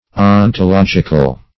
\On`to*log"ic*al\